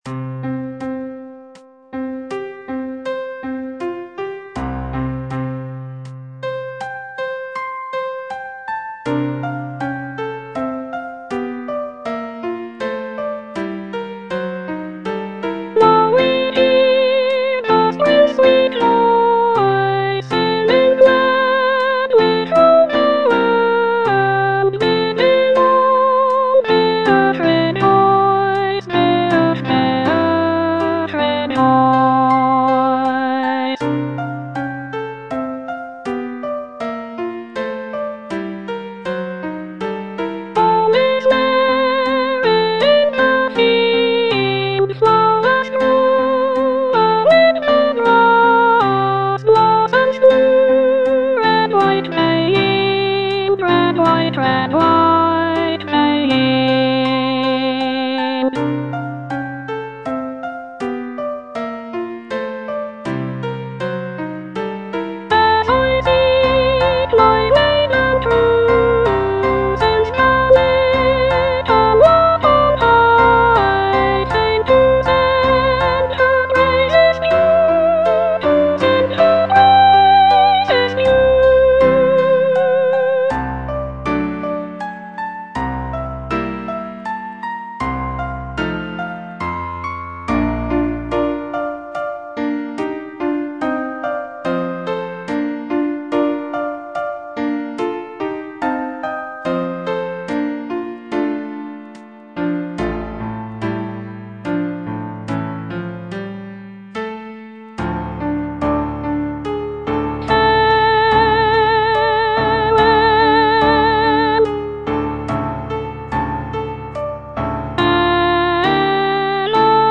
Soprano (Voice with metronome) Ads stop
choral work